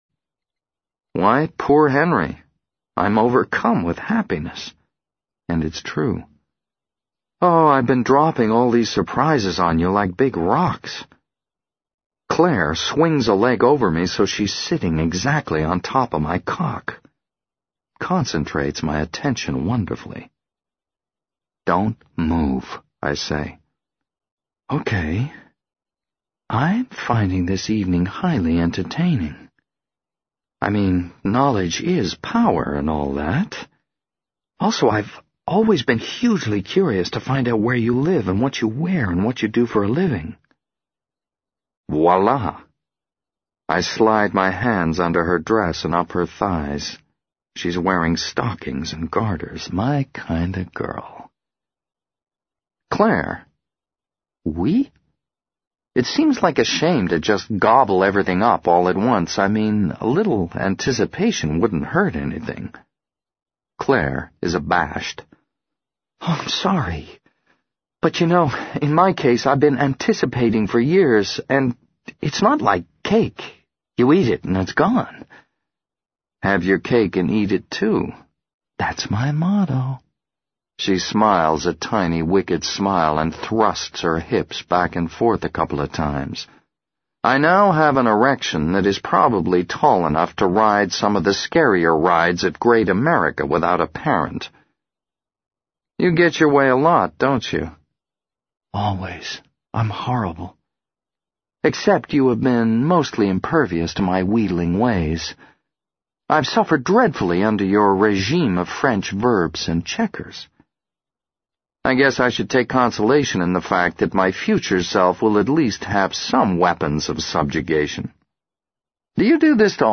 在线英语听力室【时间旅行者的妻子】17的听力文件下载,时间旅行者的妻子—双语有声读物—英语听力—听力教程—在线英语听力室